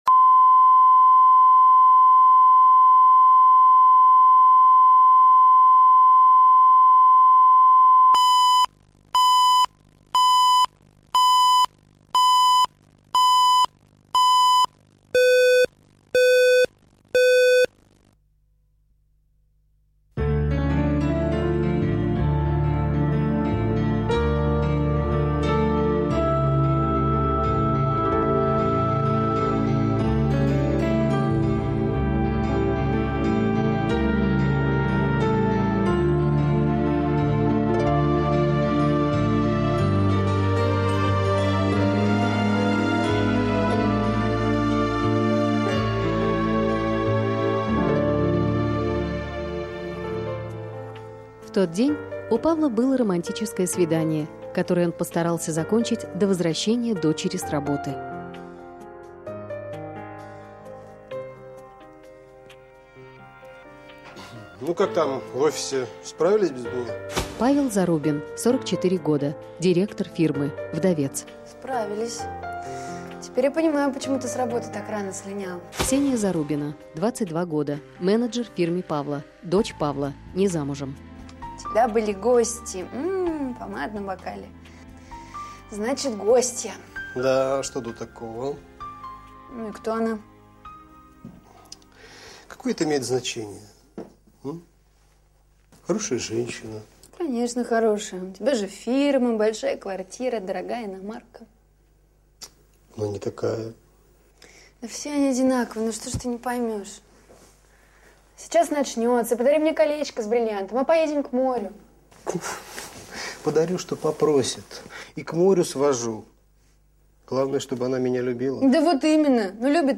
Аудиокнига Любит не любит | Библиотека аудиокниг